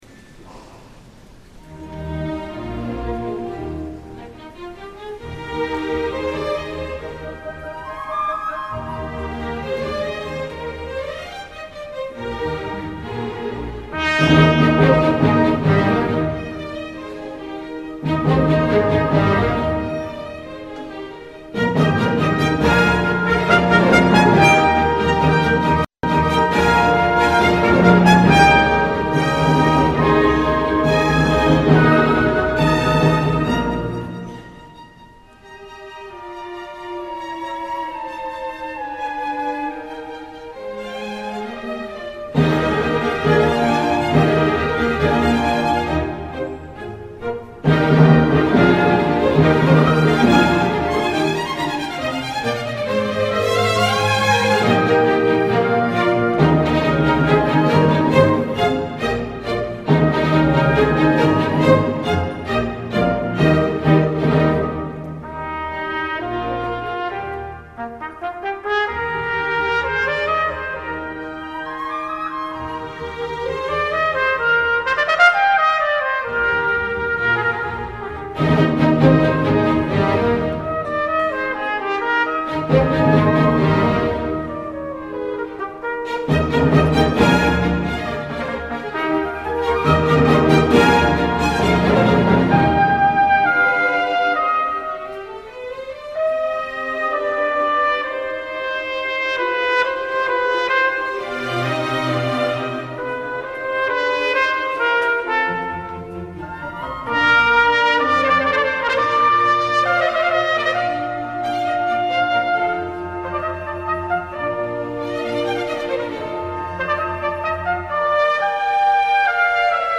Trumpet concerto
Group: Solo concerto
A concerto for solo trumpet and instrumental ensemble, customarily the orchestra.